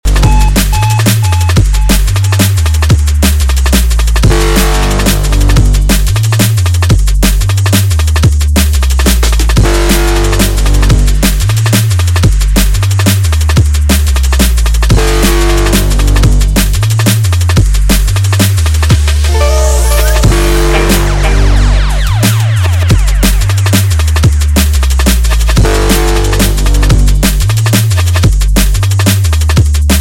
TOP >Vinyl >Drum & Bass / Jungle
Instrumental Mix